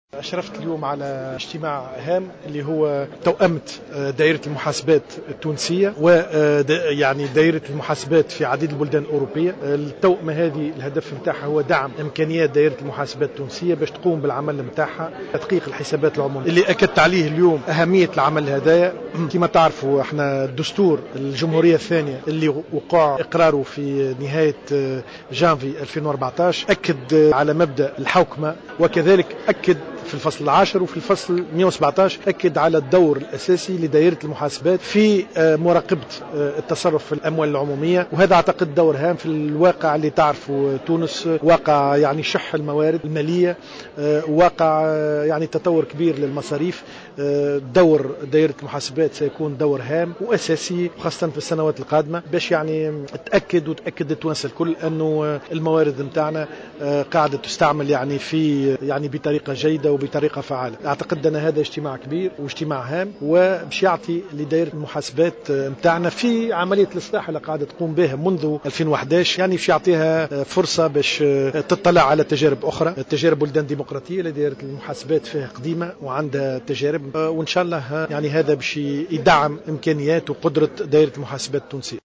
أكد وزير المالية والاقتصاد حكيم بن حمودة خلال اشرافه اليوم الجمعة 12 ديسمبر 2014 على ملتقى اختتامي لبرنامج التوأمة بين تونس والبرتغال وفرنسا حول تعزيز القدرات المؤسساتية لدائرة المحاسبات أن هذه التوأمة تهدف الى دعم امكانيات دائرة المحاسبات التونسية لتقوم بدورها في تدقيق الحسابات العمومية على حد قوله.